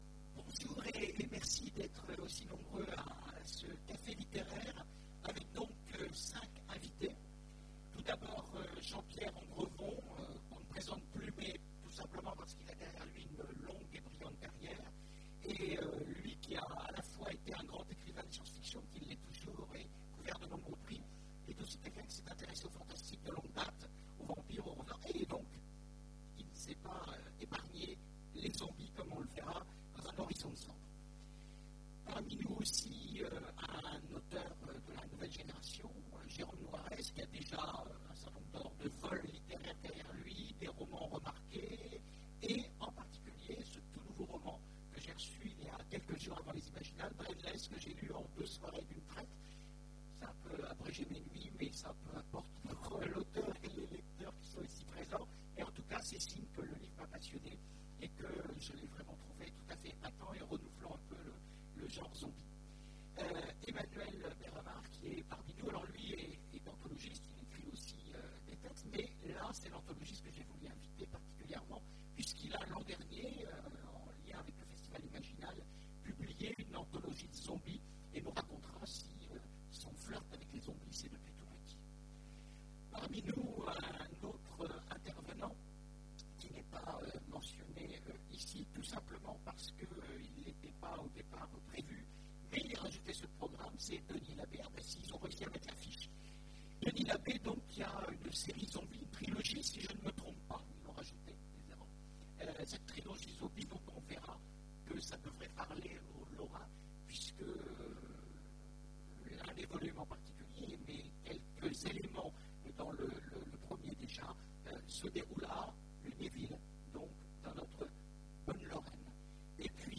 Imaginales 2015 : Conférence Zombies !